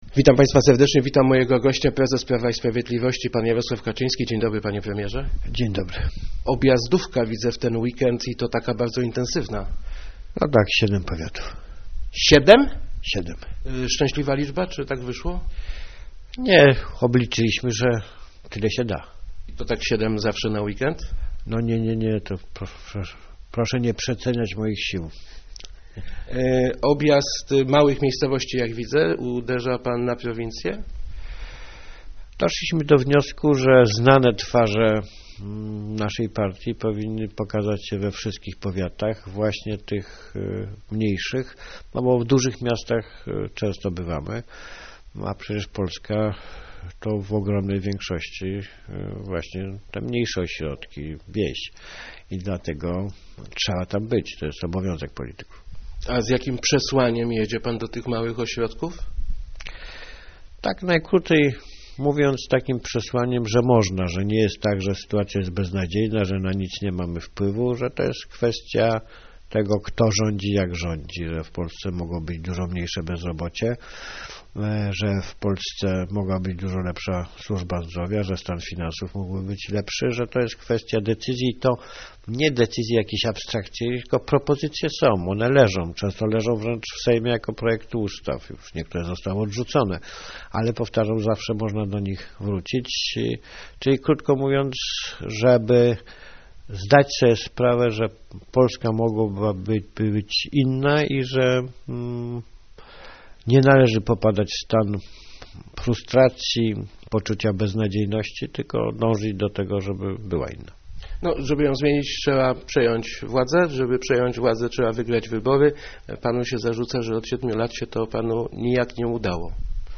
Jesteśmy jak mały Dawid w walce z potęznym Goliatem - mówił w Rozmowach Elki Jarosław Kaczyński, prezes PiS. Jego zdaniem jednak Dawid, tak jak w Biblii, wygra.